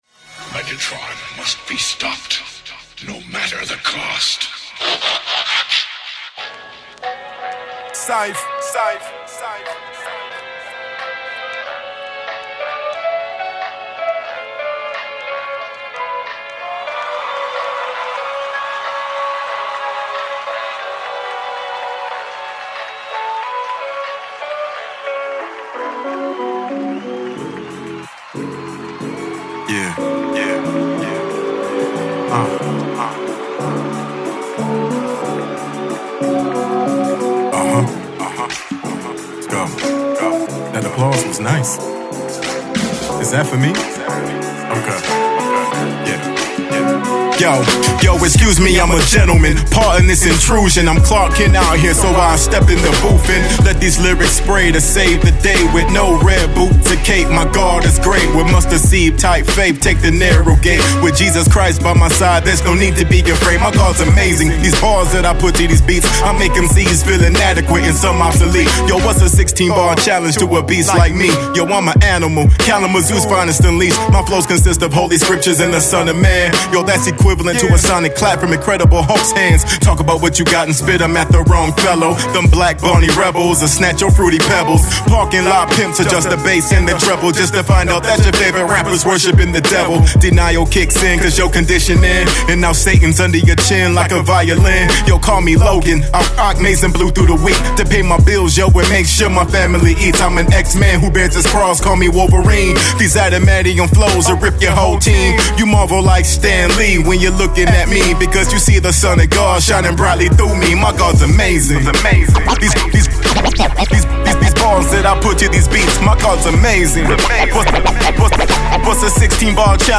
Christian/Gospel